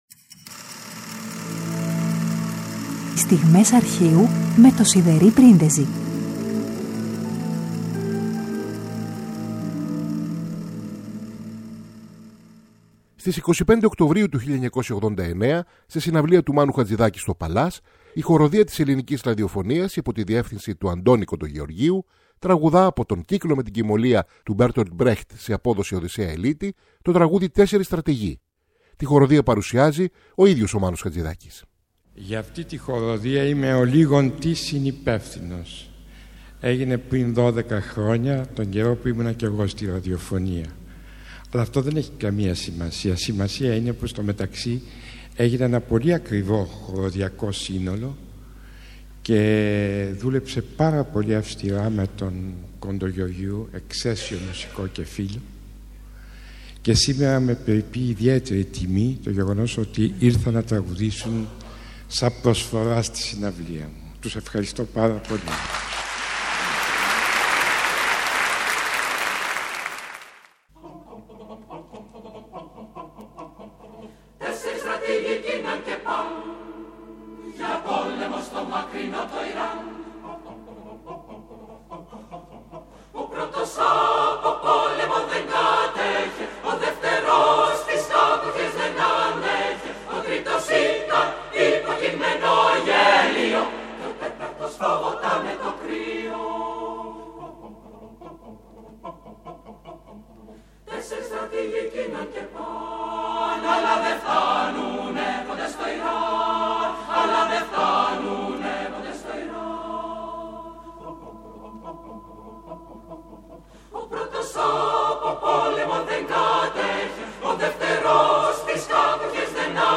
Στις 25 Οκτωβρίου 1989 από συναυλία